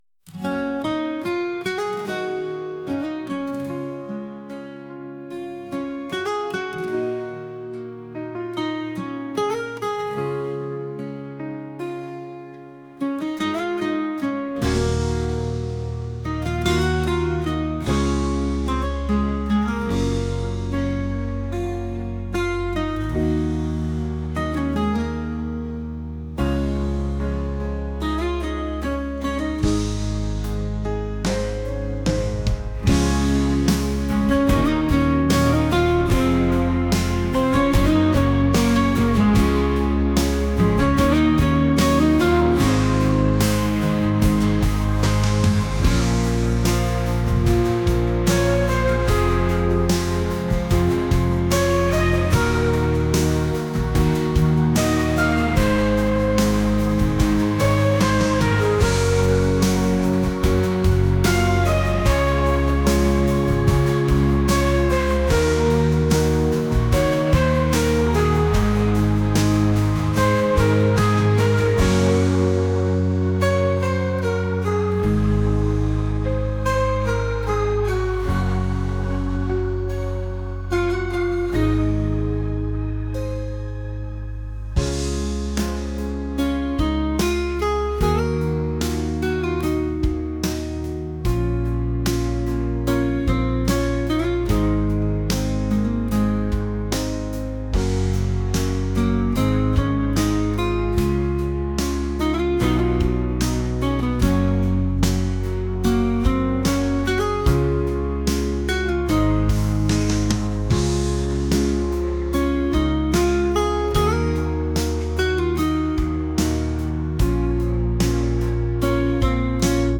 pop | acoustic | ambient